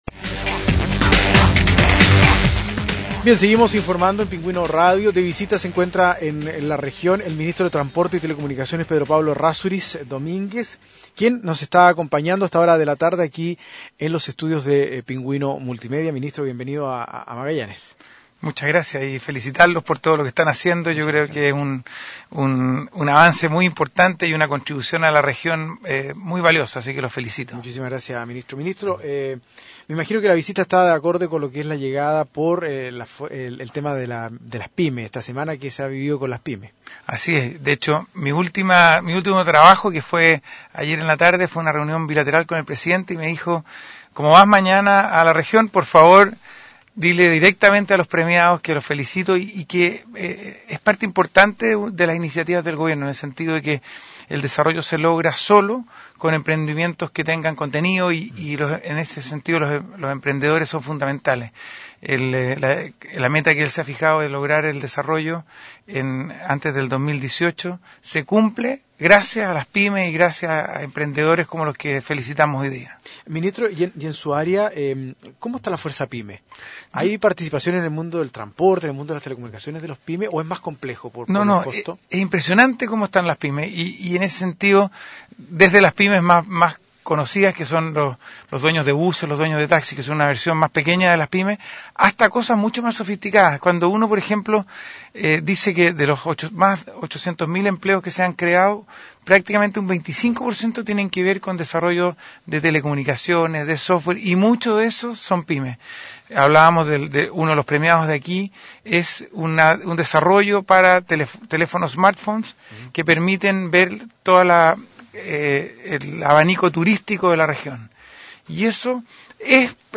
Entrevistas de Pingüino Radio - Diario El Pingüino - Punta Arenas, Chile